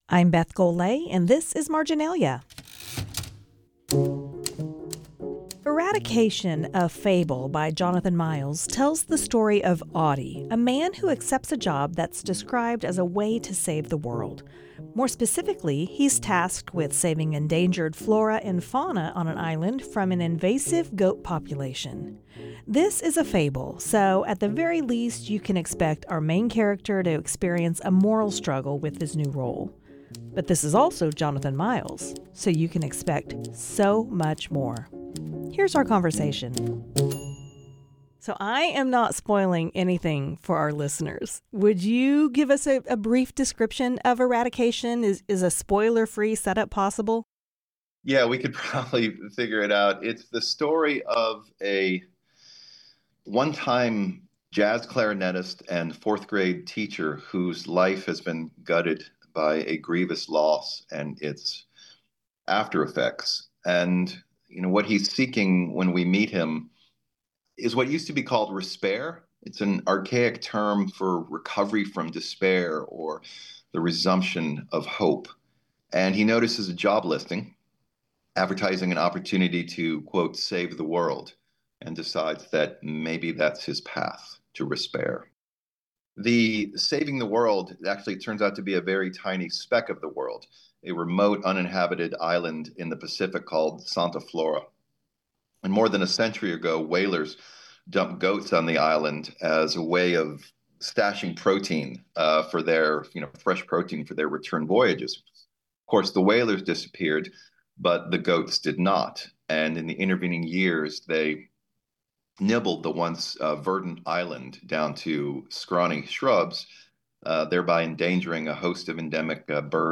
conversation